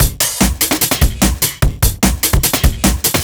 cw_148_Rust.wav